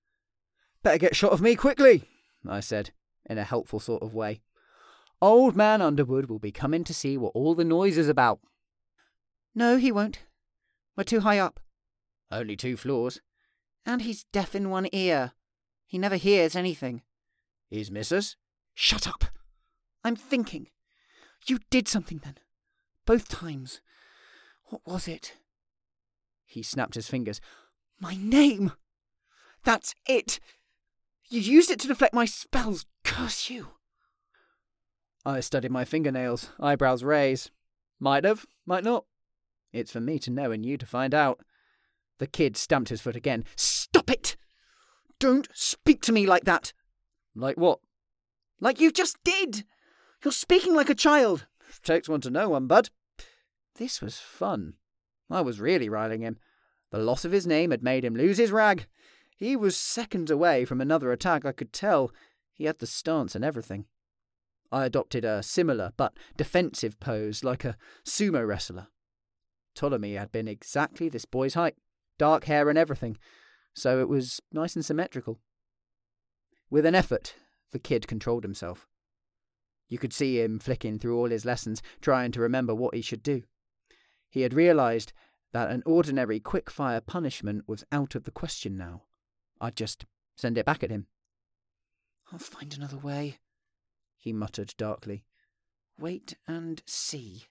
Young Adult Audiobook Sample
Male
Neutral British
Youthful
audiobook-ya-sample-1.mp3